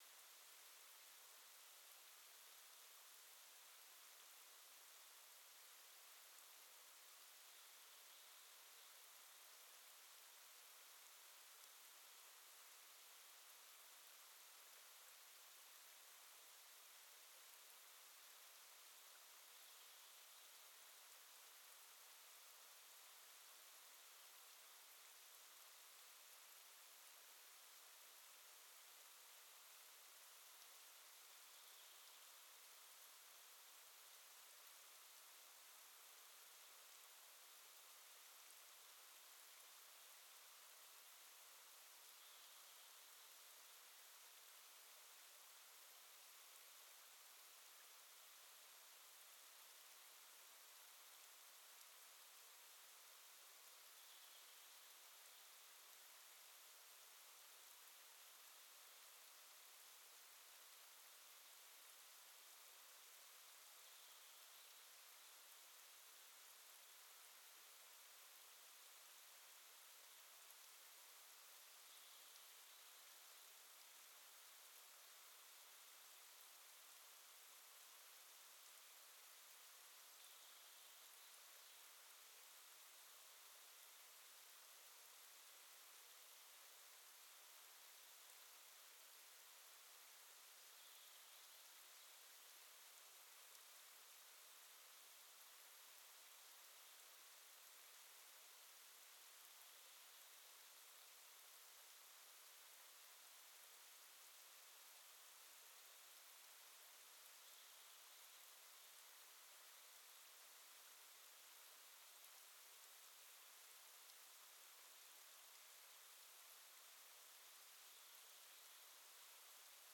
Quellrauschen in Bänder geteilt 16000.wav